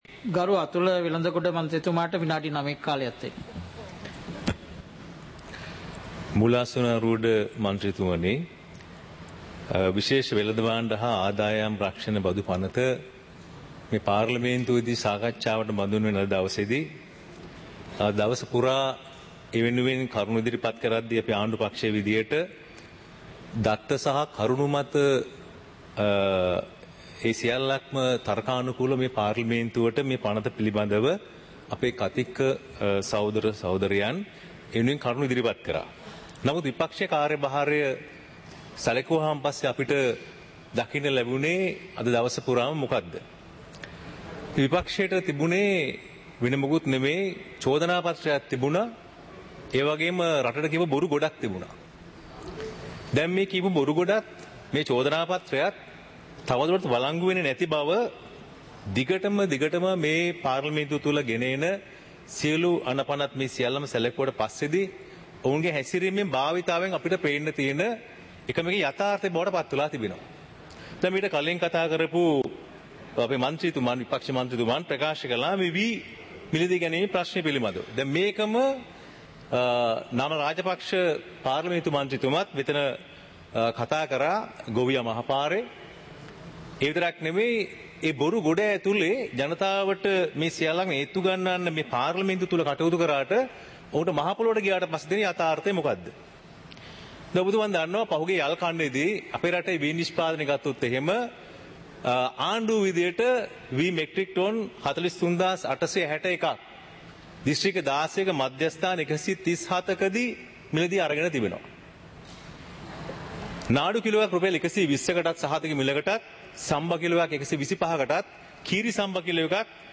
இலங்கை பாராளுமன்றம் - சபை நடவடிக்கைமுறை (2026-02-18)
நேரலை - பதிவுருத்தப்பட்ட